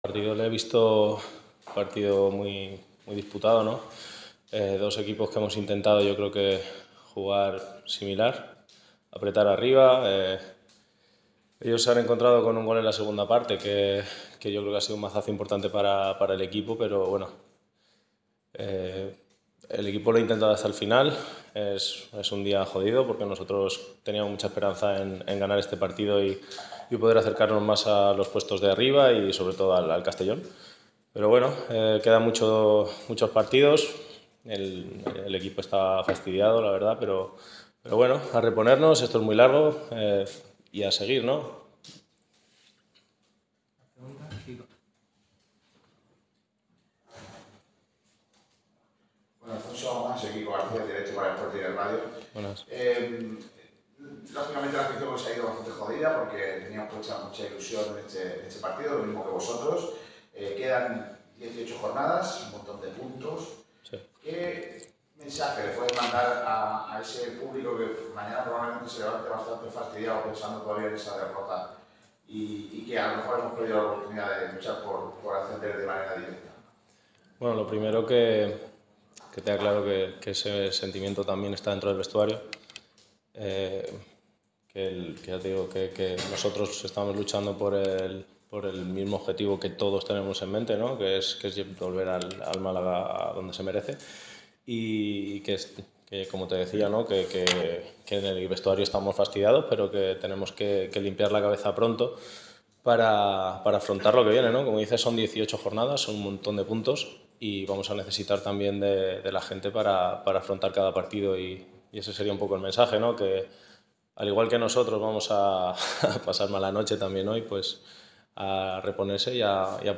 El guardameta malaguista ha comparecido ante los medios tras la derrota de los boquerones en La Rosaleda ante el Castellón (0-1). El portero se muestra crítico pero confía en que el trabajo del equipo se pueda ver recompensado pese al varapalo de hoy.